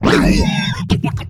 alianhit2.ogg